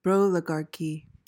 PRONUNCIATION:
(BRO-luh-gar-kee)